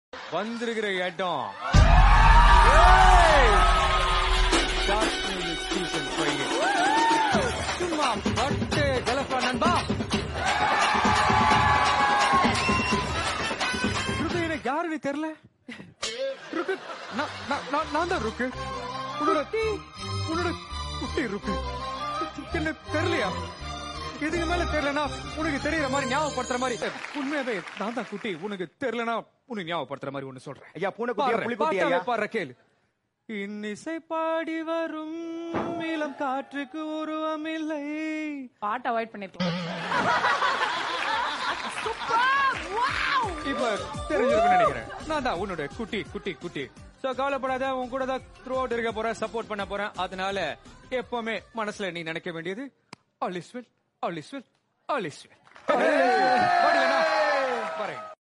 𝚠𝚘𝚠 𝚜𝚞𝚙𝚎𝚛 𝚜𝚊𝚖𝚎 𝚝𝚑𝚊𝚕𝚊𝚙𝚊𝚝𝚑𝚢 𝚟𝚘𝚒𝚌𝚎 sound effects free download